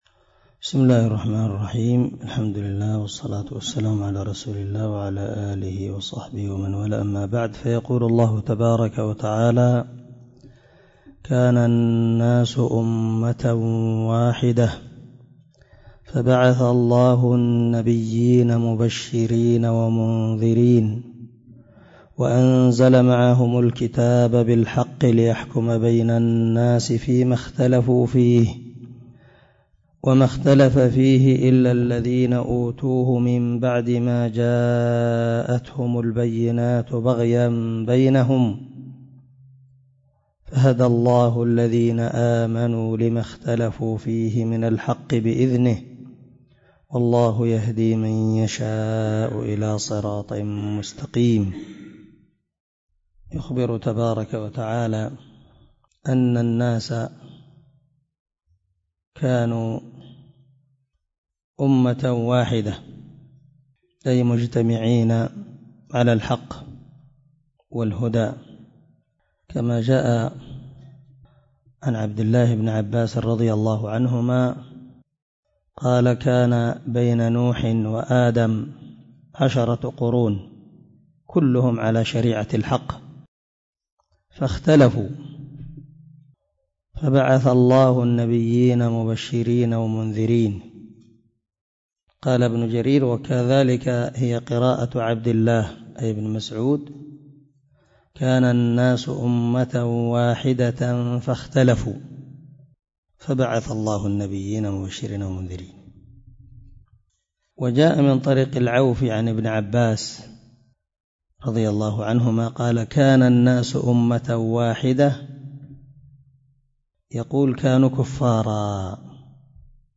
102الدرس 92 تفسير آية ( 213 ) من سورة البقرة من تفسير القران الكريم مع قراءة لتفسير السعدي